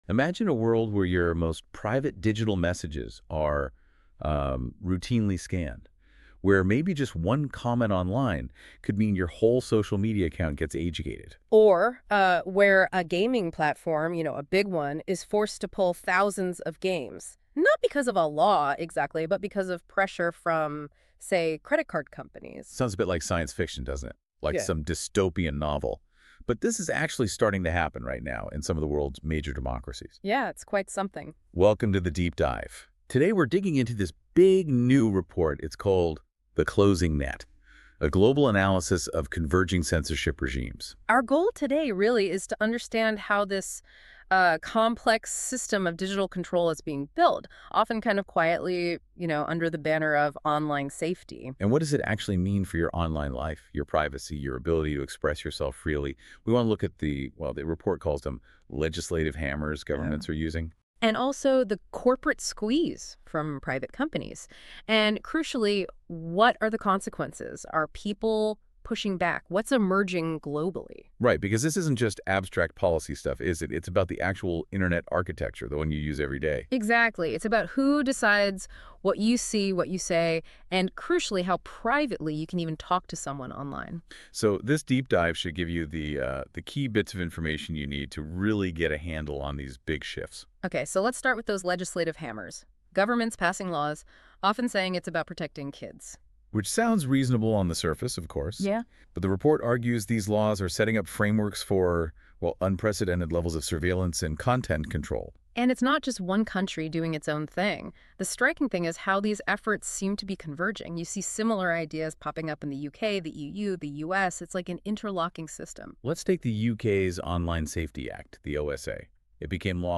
Index The report: what most people are after (Under construction) The AI generated podcast version of the same report: if you wanna listen to the report in a ‘podcast’ like manner while doing other stuff.